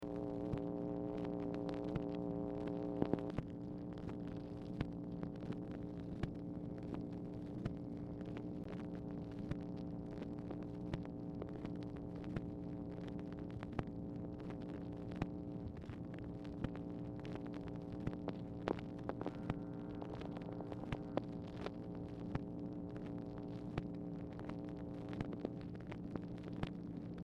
Format Dictation belt
Location Of Speaker 1 Oval Office or unknown location
Series White House Telephone Recordings and Transcripts Speaker 2 MACHINE NOISE Specific Item Type Telephone conversation